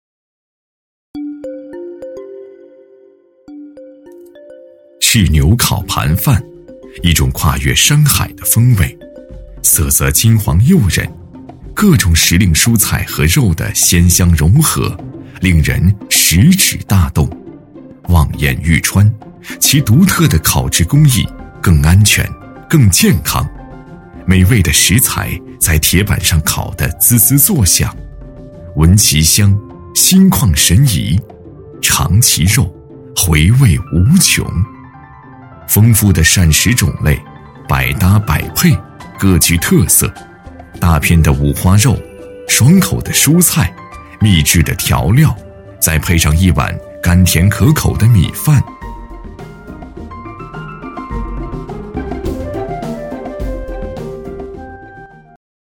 特点：大气浑厚 稳重磁性 激情力度 成熟厚重
风格:浑厚配音
男26试音-炽牛烤盘饭2.MP3